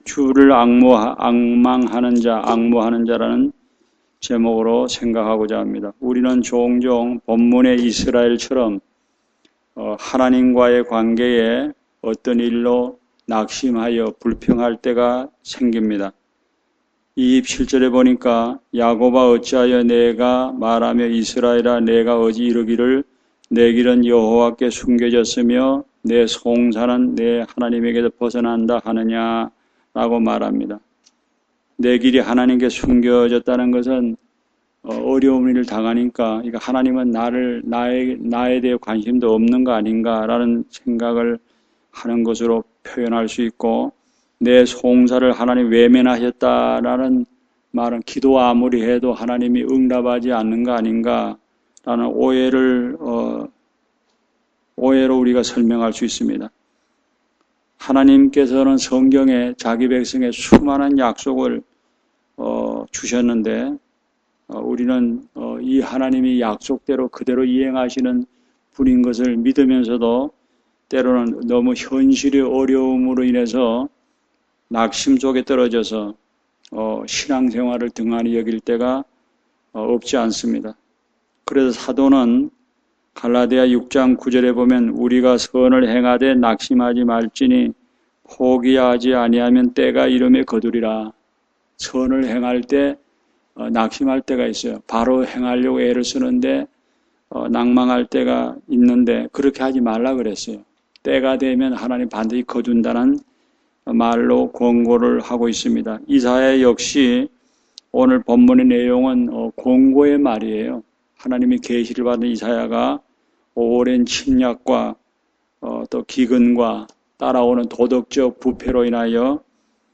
Series: 주일설교